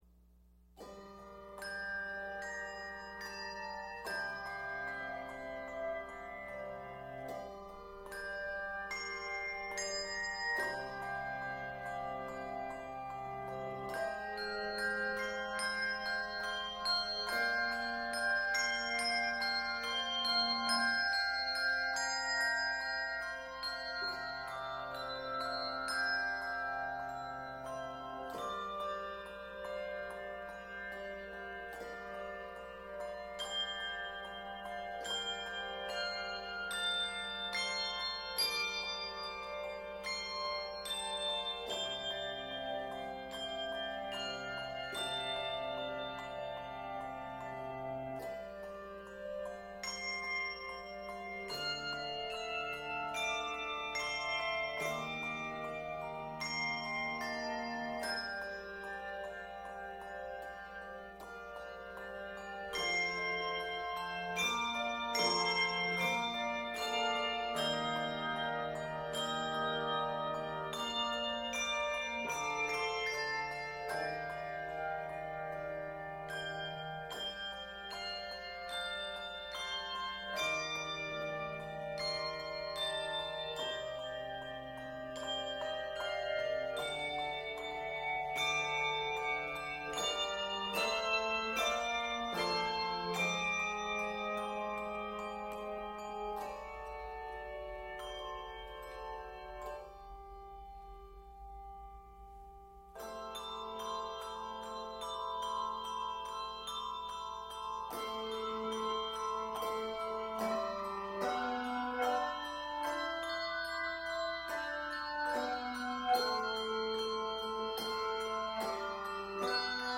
Octaves: 2-5